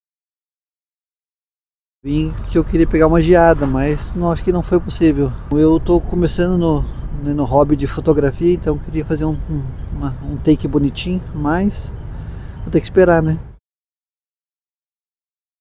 A reportagem da CBN Curitiba esteve no Jardim Botânico e encontrou muita gente que escolheu começar o dia ao ar livre.